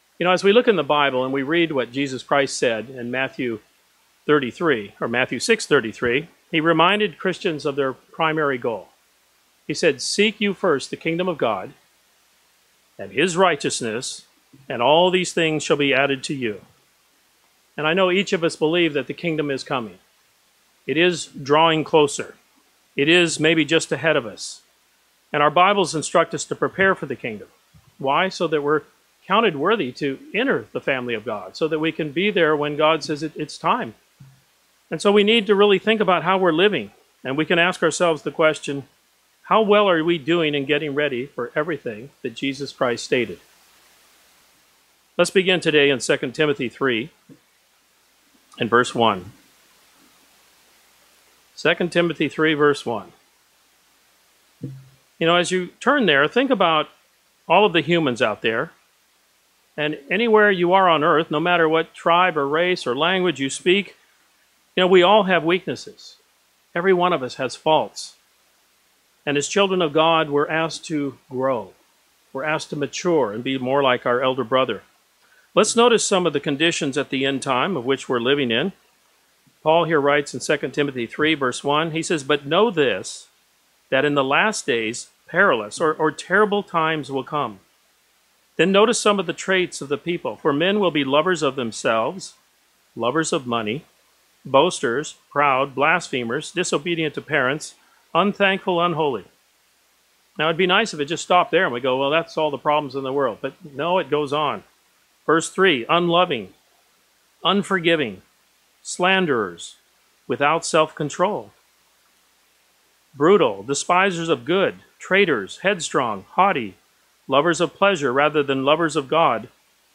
This sermon discusses seven areas of self that each Christian should stop and reflect on. Are we willing to change ourselves using God’s help?
Given in Tampa, FL